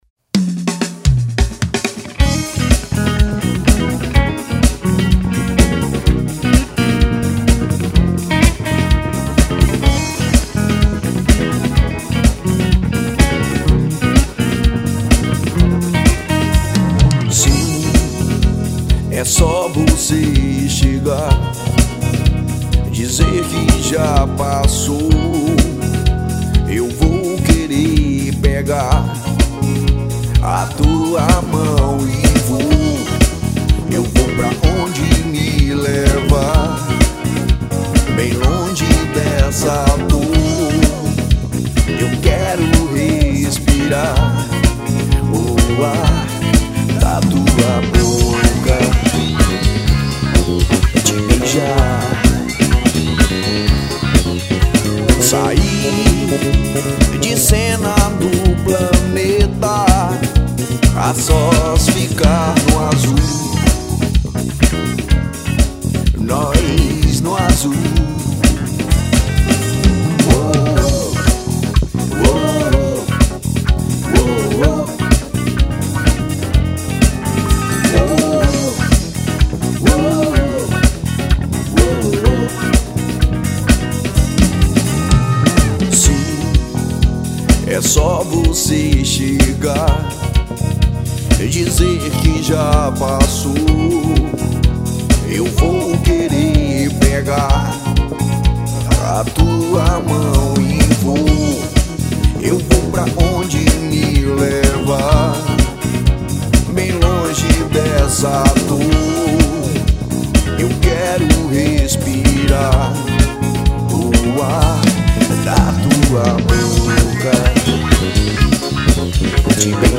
1960   03:05:00   Faixa:     Rock Nacional